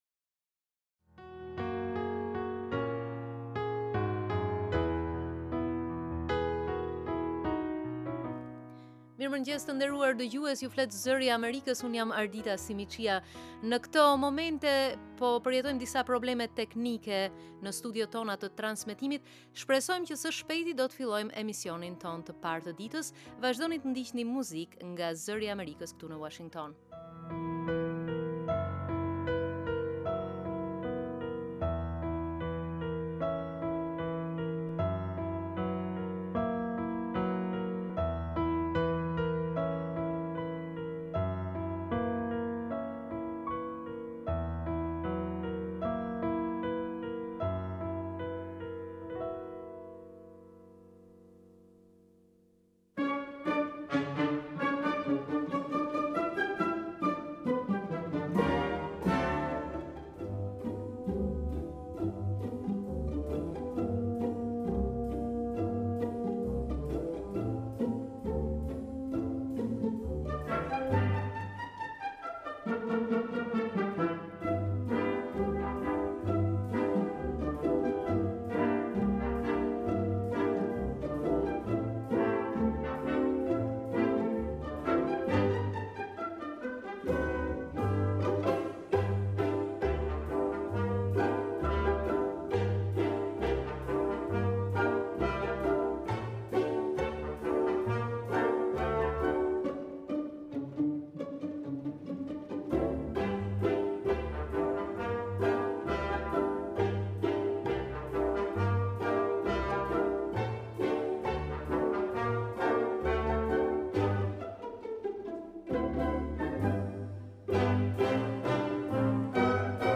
Lajmet e mëngjesit